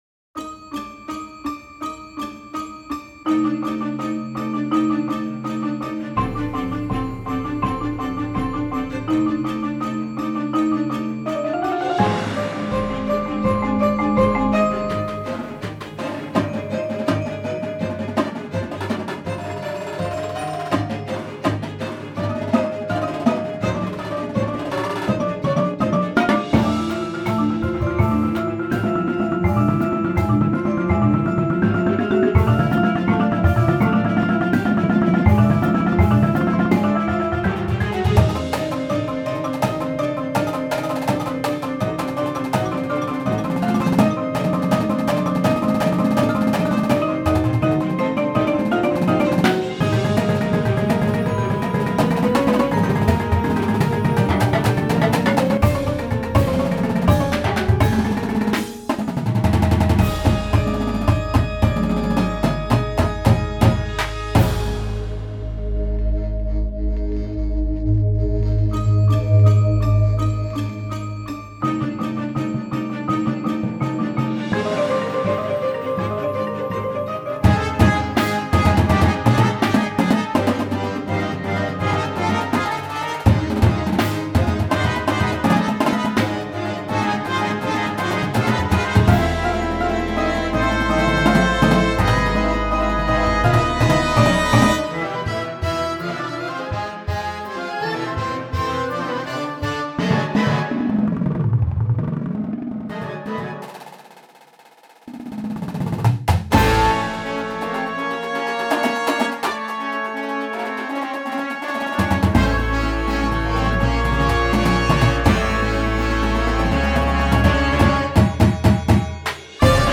Marching Band Shows
Winds
Percussion